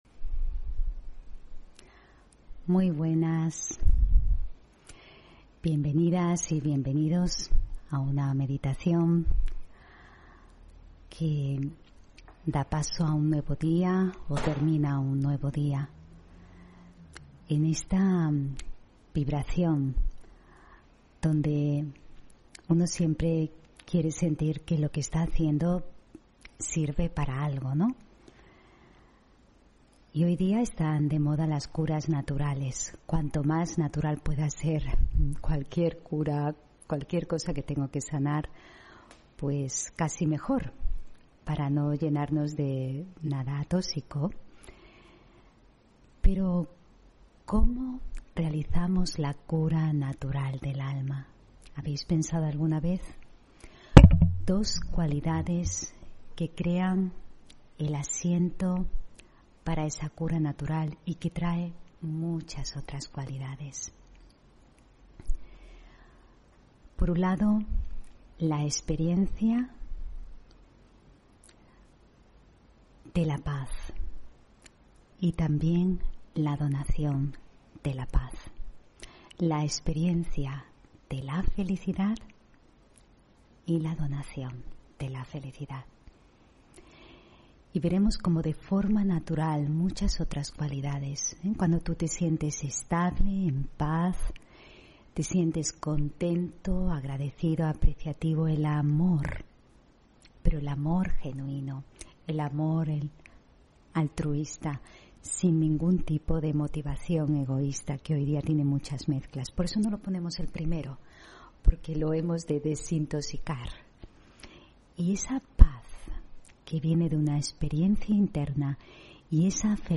Meditación de la mañana: Cura natural